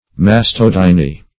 Search Result for " mastodyny" : The Collaborative International Dictionary of English v.0.48: Mastodynia \Mas`to*dyn"i*a\, Mastodyny \Mas*tod"y*ny\, n. [NL. mastodynia, fr. Gr. masto`s the breast + ? pain.] (Med.) Pain occuring in the mamma or female breast, -- a form of neuralgia.